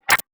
UI_SFX_Pack_61_19.wav